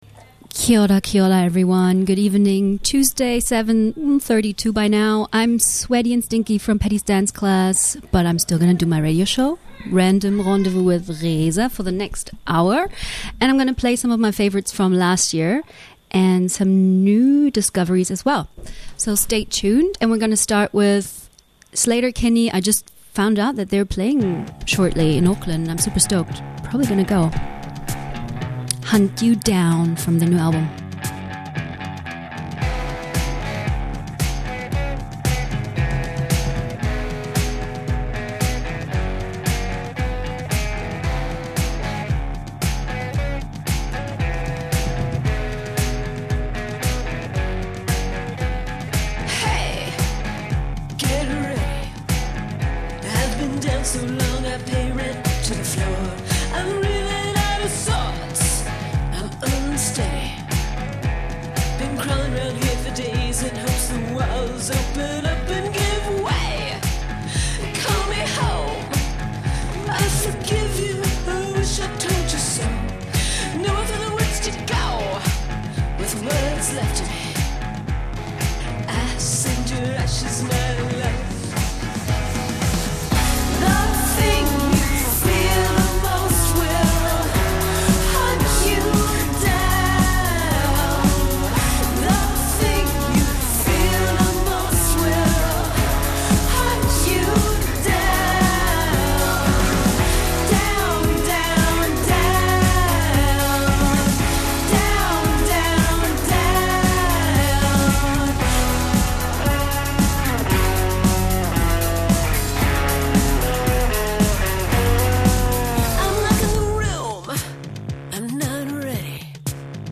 Random means its got everything: punk-rock, indie, electro and classical music